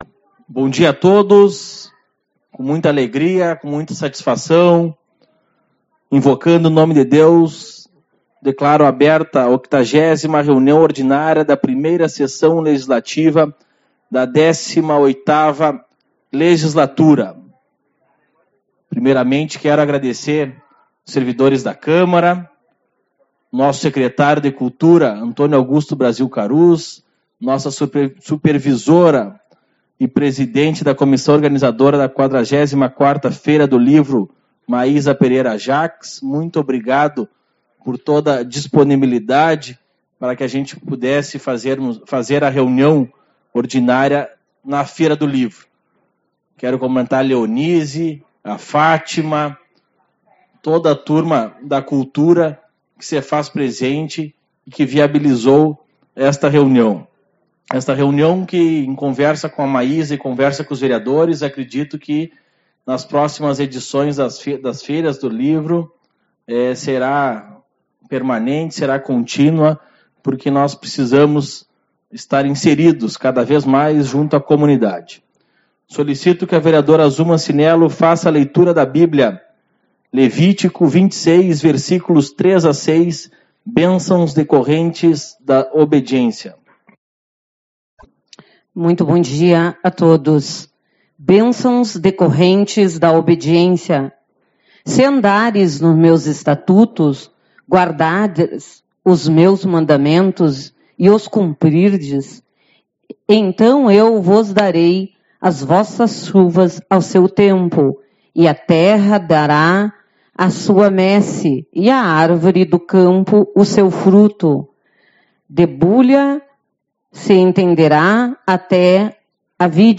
02/12 - Reunião Ordinária